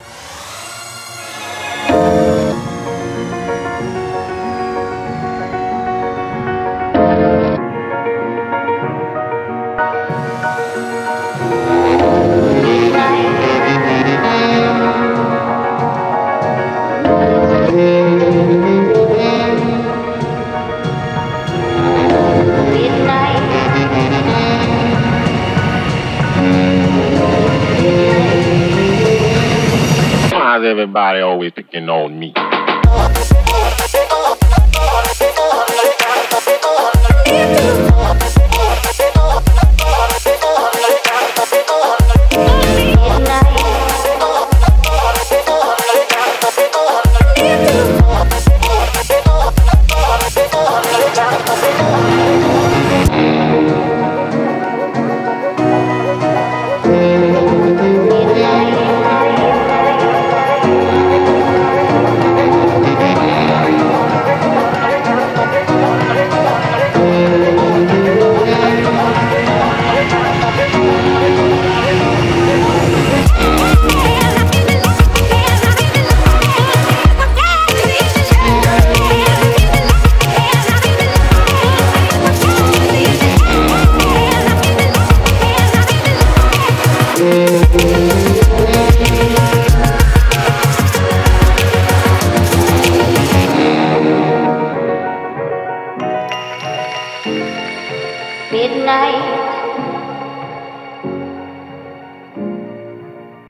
BPM95-95
Audio QualityMusic Cut